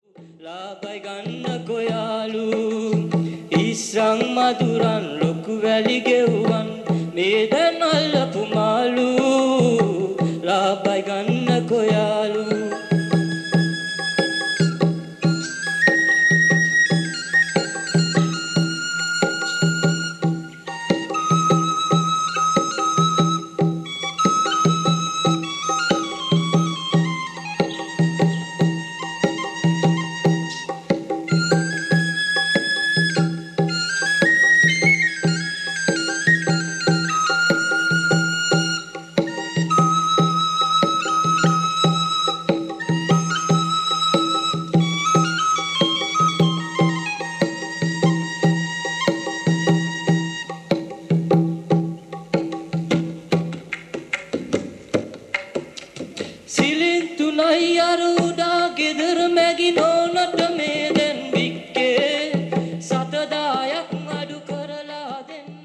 Voix et dolaki
flûte et tabla